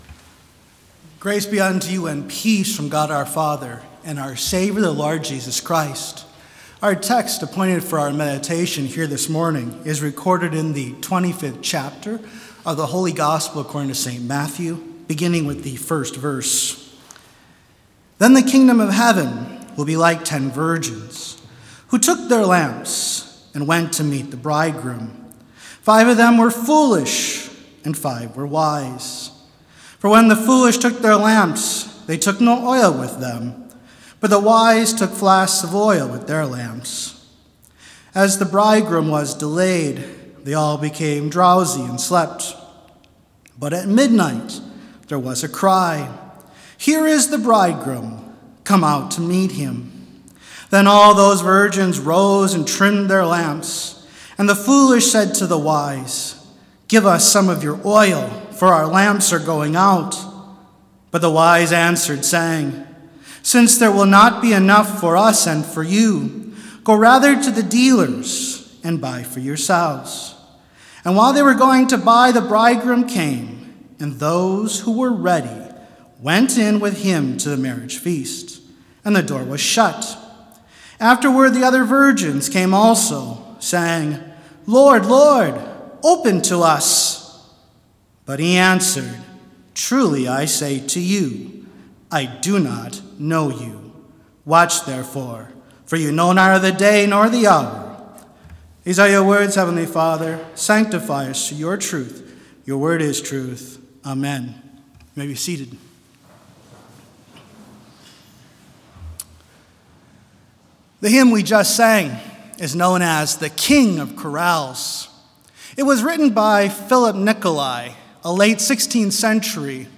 Complete service audio for Chapel - November 29, 2021